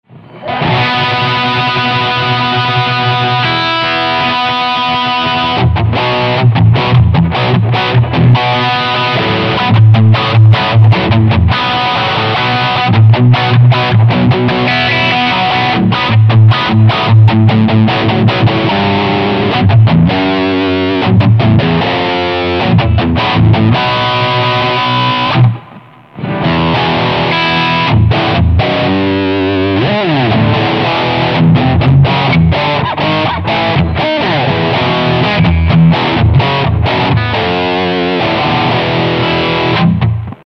Zu hören sind meine Gold Top (mit Duncan Seth Lover PUs), der Demonizer und eine Prise Hall damit es nicht so knochentrocken ist:
Demonizer - Hard Rock
das ist dieselbe Einstellung wie beim aussenjam #27, nur es ist eine andere Gitarre und ich spiele halt was anderes